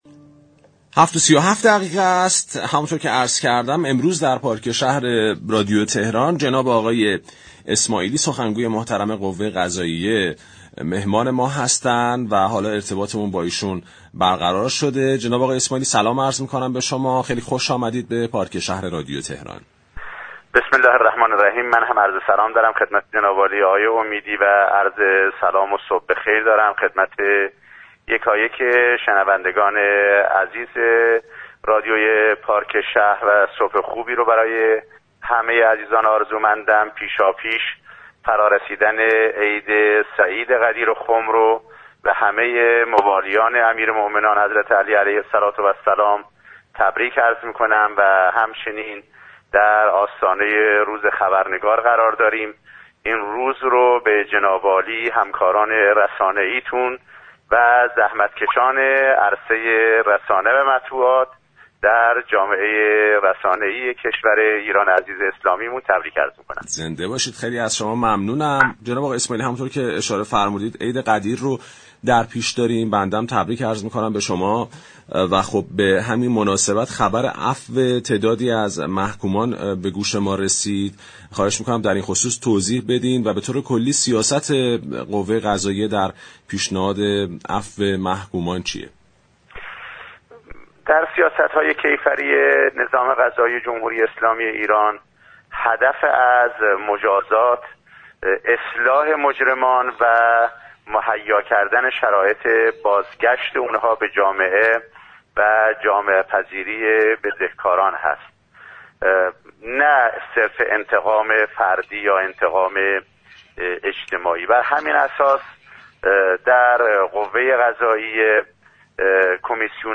سخنگوی قوه قضائیه درباره عفومحكومان، نقش خبرنگاران در گزارش مفاسد اقتصادی، پرونده های زمین خواری و بازگشت ارزهای خارج شده به كشور و همچنین دلیل مرگ قاضی منصوری و بازداشت مدیران باشگاه پرسپولیس با پارك شهر رادیو تهران گفتگو كرد.